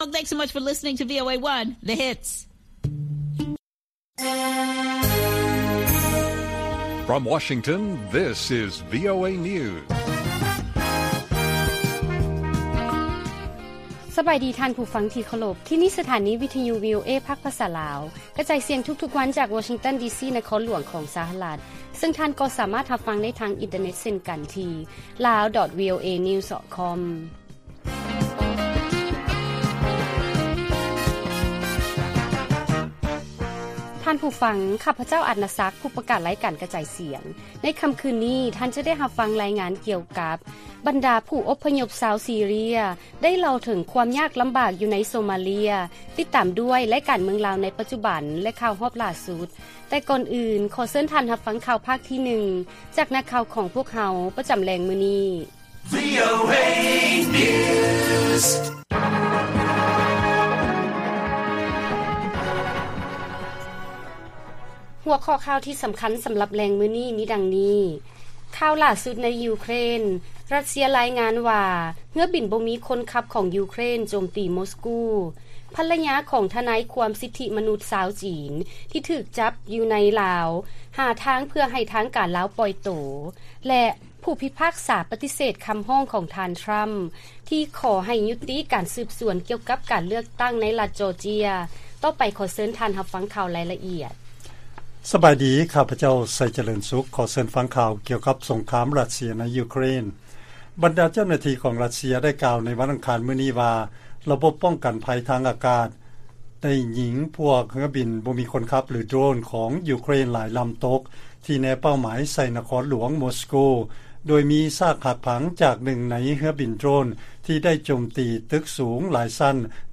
ວີໂອເອພາກພາສາລາວ ກະຈາຍສຽງທຸກໆວັນ, ຫົວຂໍ້ຂ່າວສໍາຄັນໃນມື້ນີ້ມີ: 1. ຣັດເຊຍລາຍງານວ່າ ເຮືອບິນບໍ່ມີຄົນຂັບຂອງ ຢູເຄຣນ ໂຈມຕີ ມົສກູ, 2. ພັນລະຍາຂອງທະນາຍຄວາມສິດທິມະນຸດ ຊາວຈີນ ທີ່ຖືກຈັບຢູ່ໃນລາວ ຫາທາງເພື່ອໃຫ້ທາງການລາວປ່ອຍໂຕ, ແລະ 3. ຜູ້ພິພາກສາປະຕິເສດຄຳຮ້ອງຂອງ ທ່ານທຣຳ ທີ່ຂໍໃຫ້ຍຸຕິການສືບສວນກ່ຽວກັບການເລືອກ.